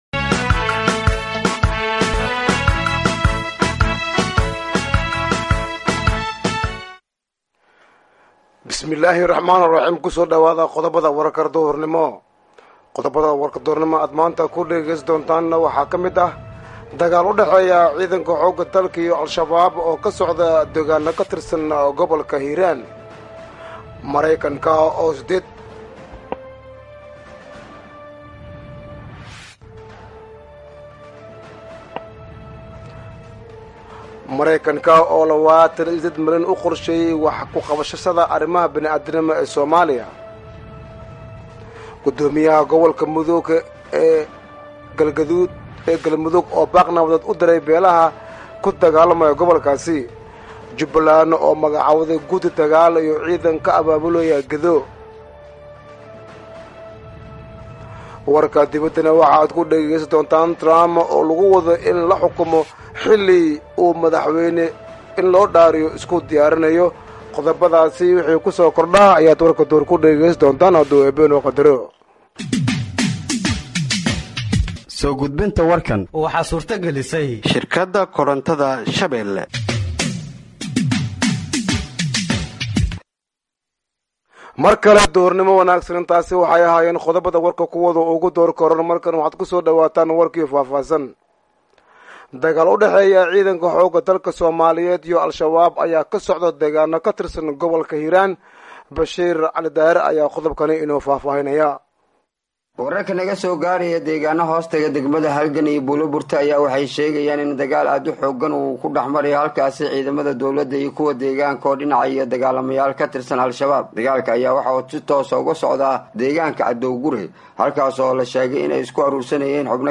Dhageeyso Warka Duhurnimo ee Radiojowhar 04/01/2025